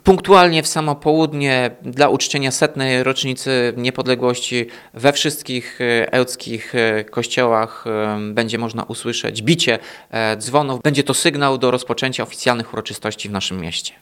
Mówił prezydent Ełku Tomasz Andrukiewicz.